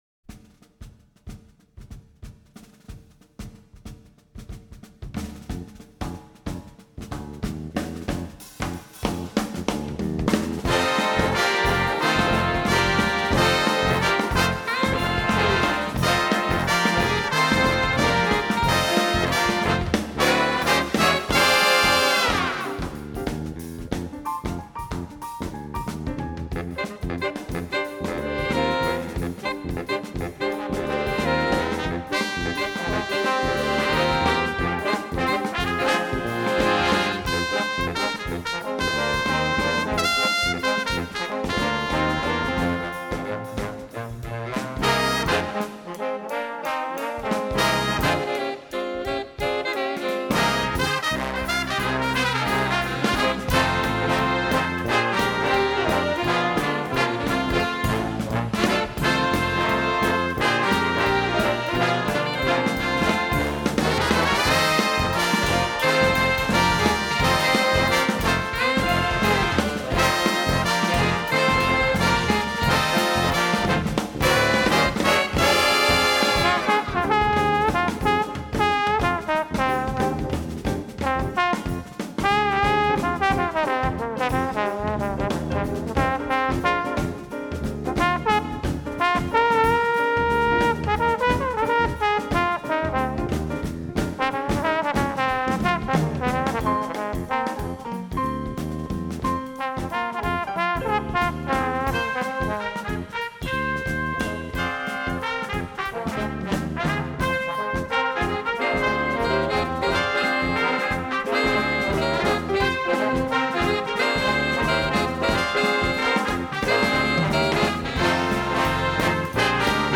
Composer: Spiritual
Voicing: Jazz Ensemble